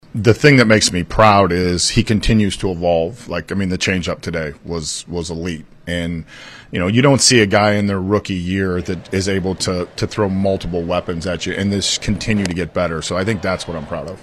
Manager Derek Shelton says Skenes is special.